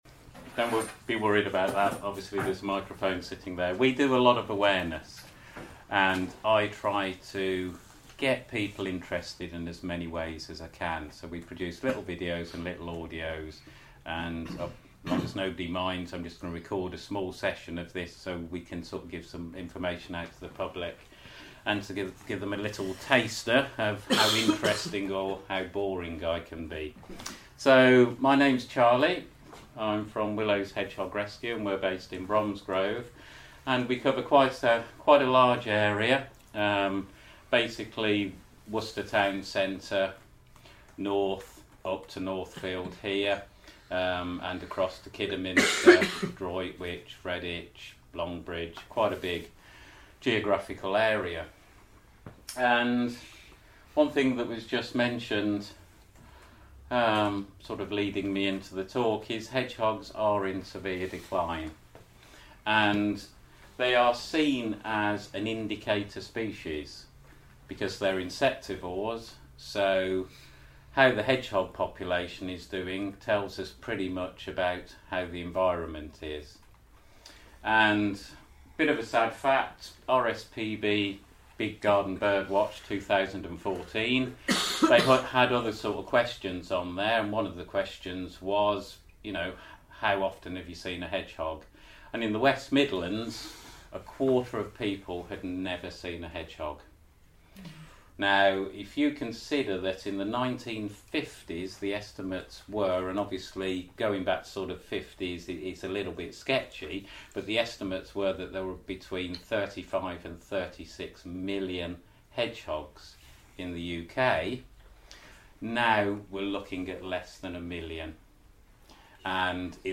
A taster of one of our hedgehog talks given to groups.
A fifteen minute audio compilation of short segments from a much longer talk given in May 2014 to a conservation group in Birmingham looking at hedgehogs, rescue, issues & the wider environemt.